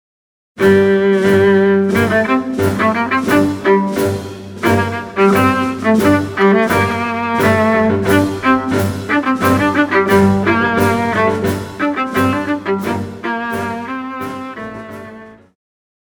Pop
Viola
Orchestra
Instrumental
Only backing